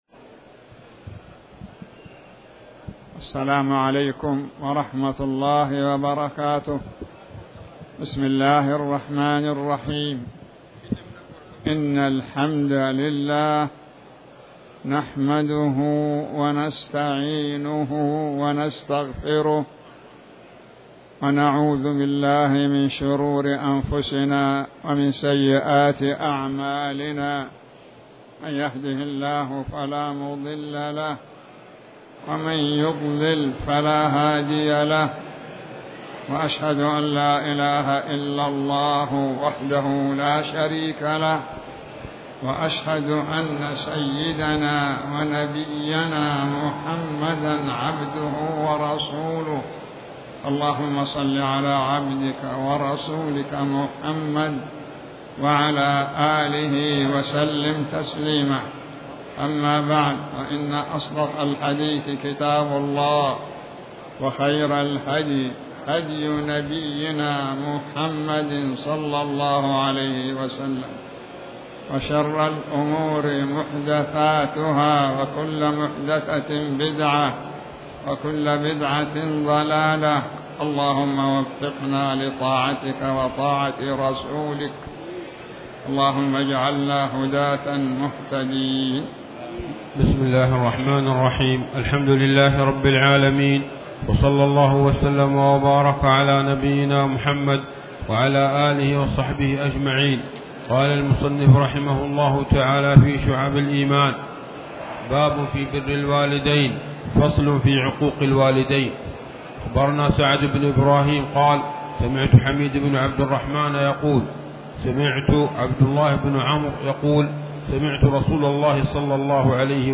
تاريخ النشر ١ محرم ١٤٤٠ هـ المكان: المسجد الحرام الشيخ